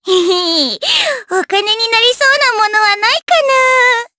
May-Vox_Victory_jp.wav